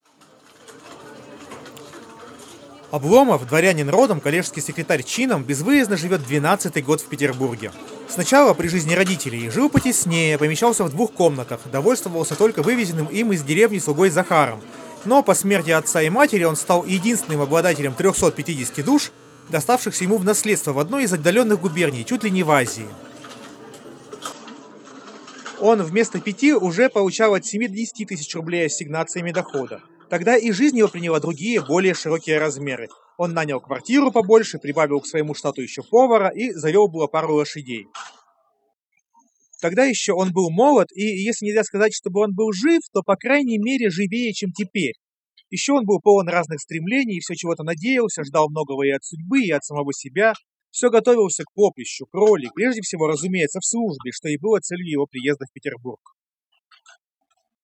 noise.mp3